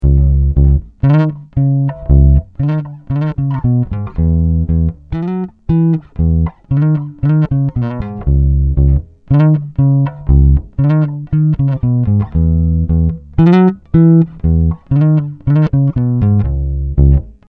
Bassriff-Quiz
:D is aber auch mit den ganzen synkopen ohne beat auch doof